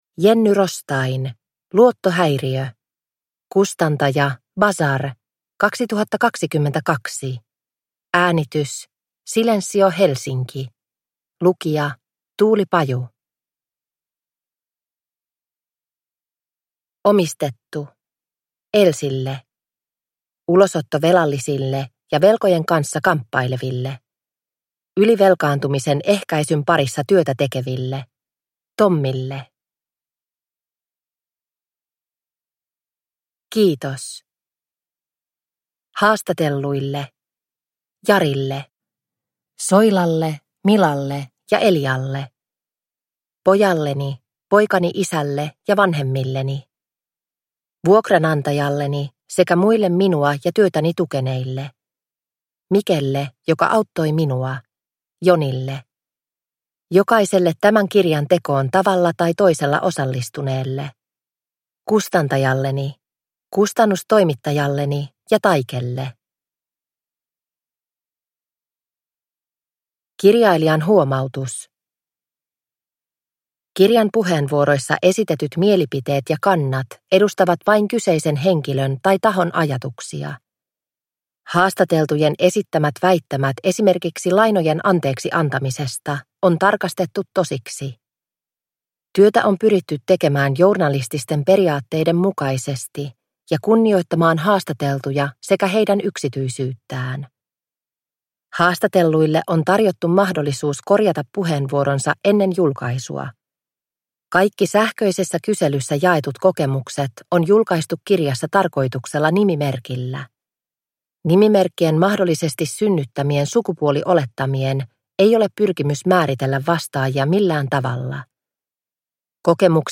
Luottohäiriö – Ljudbok – Laddas ner